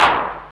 CLAP     7-R.wav